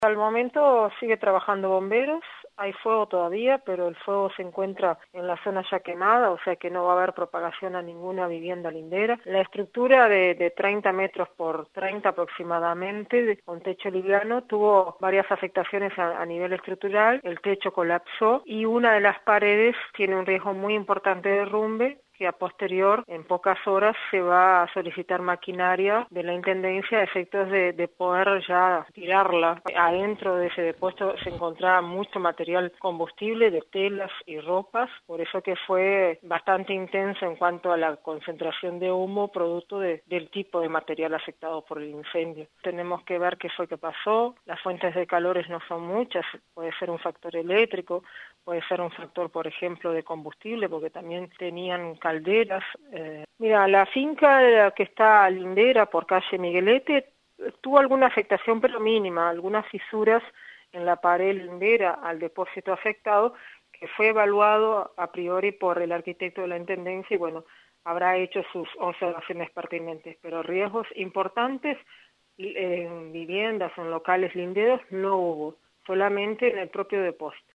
habló con Camino a Casa, explicó cómo se encuentra la zona y aseguró que no se conoce aún la causa del incendio.